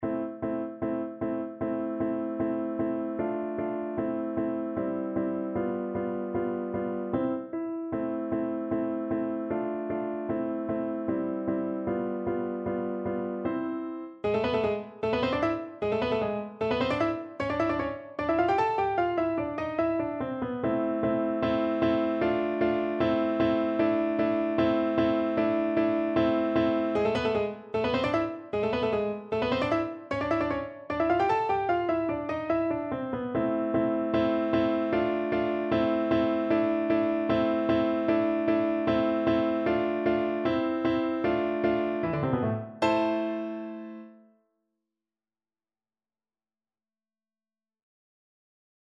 Play (or use space bar on your keyboard) Pause Music Playalong - Piano Accompaniment Playalong Band Accompaniment not yet available transpose reset tempo print settings full screen
2/4 (View more 2/4 Music)
A minor (Sounding Pitch) (View more A minor Music for Violin )
Allegro scherzando (=152) (View more music marked Allegro)
Classical (View more Classical Violin Music)